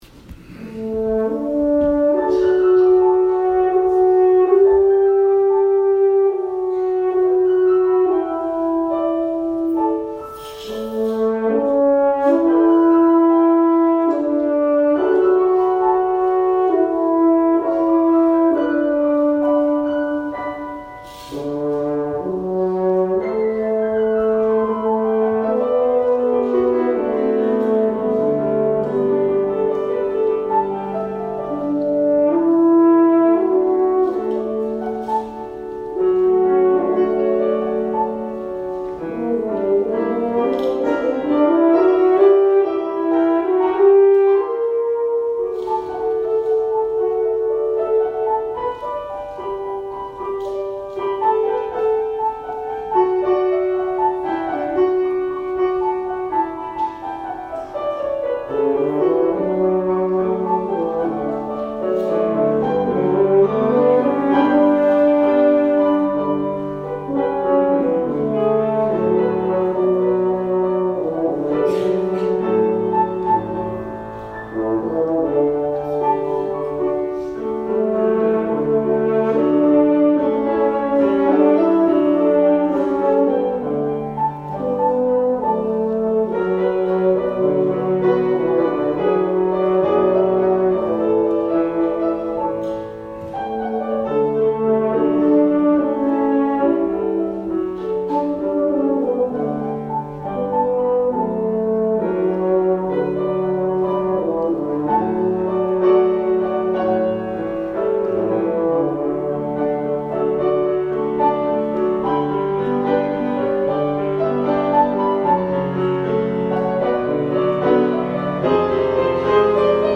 Offertory: He Leadeth Me
French Horn
Piano